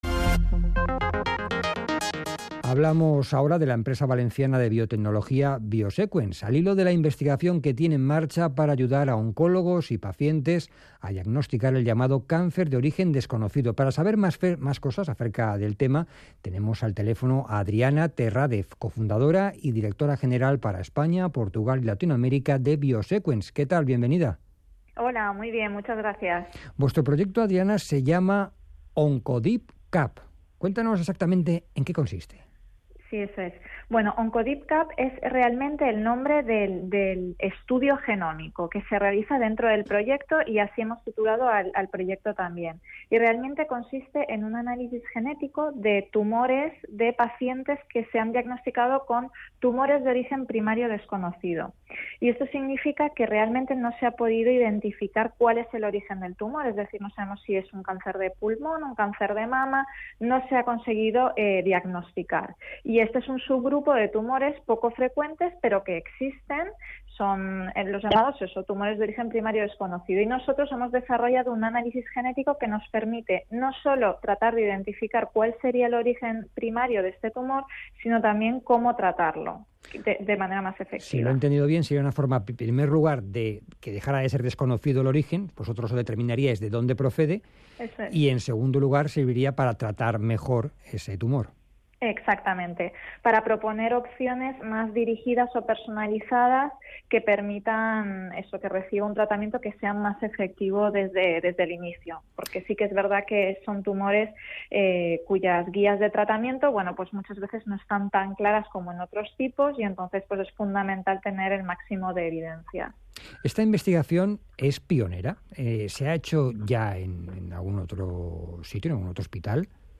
23.11.2017 Radio OncoDEEP CUP
es entrevistada en RNE para hablar sobre el proyecto de investigación OncoDEEP CUP que lleva a cabo la compañía biotecnológica y que cuenta con la cofinanciación de ICEX y del Fondo Europeo de Desarrollo Regional.